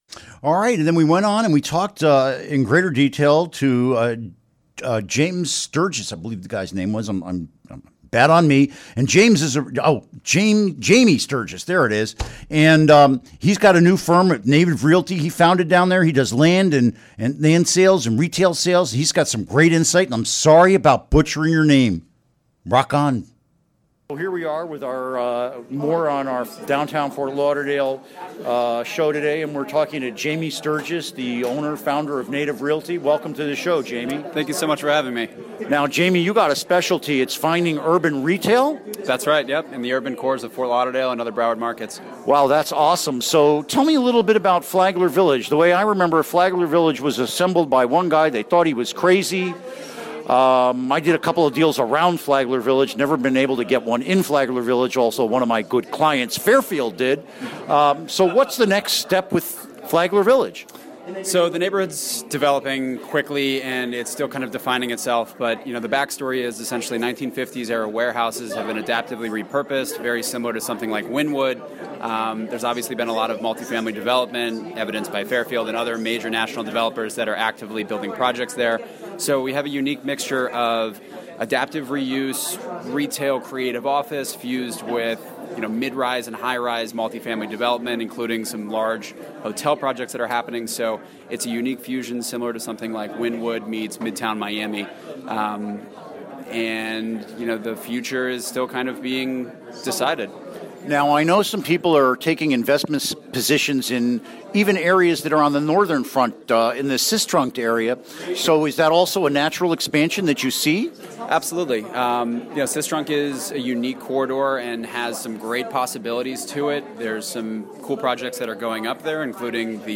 Interview Segment (To download, right-click and select “Save Link As”.)